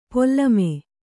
♪ pollame